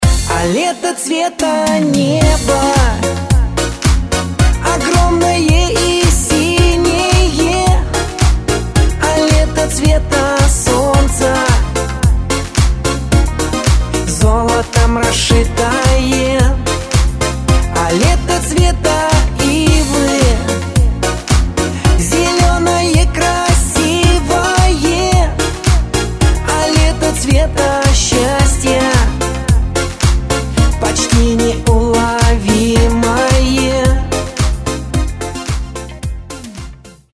поп
Ретро рингтоны